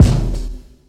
Stck_brk_kick_1.wav